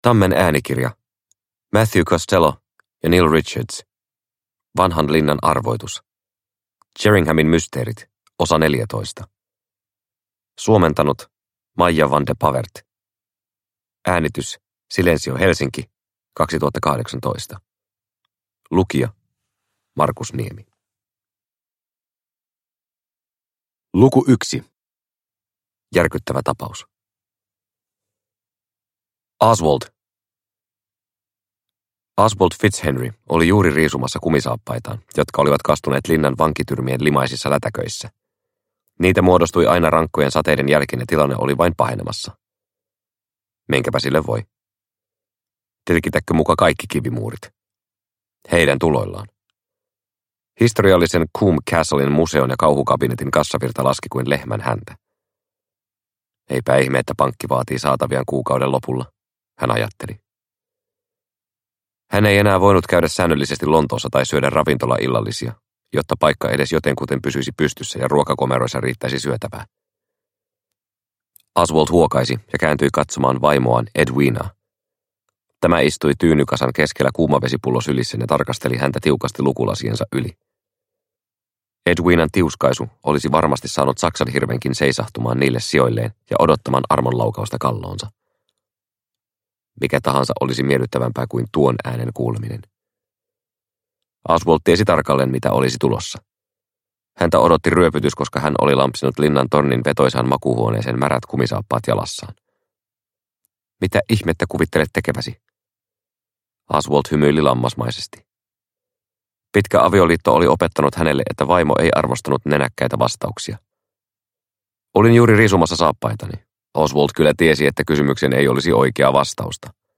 Vanhan linnan arvoitus – Ljudbok – Laddas ner